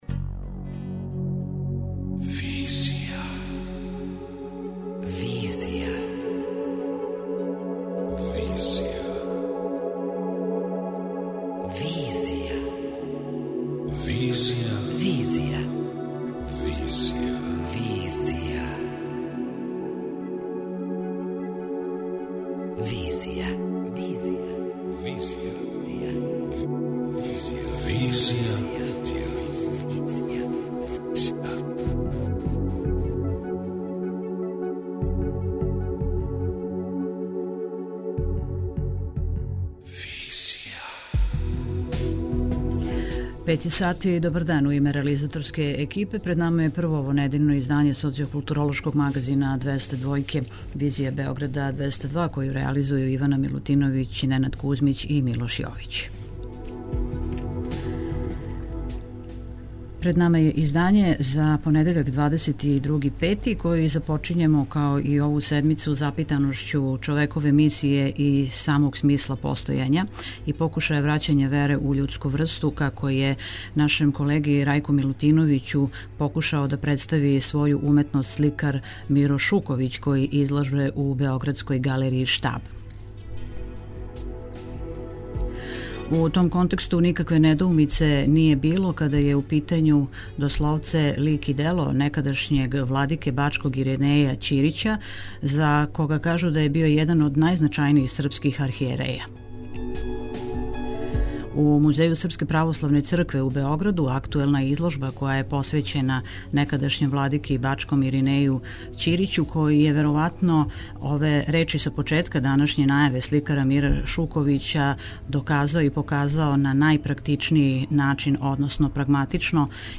преузми : 26.38 MB Визија Autor: Београд 202 Социо-културолошки магазин, који прати савремене друштвене феномене.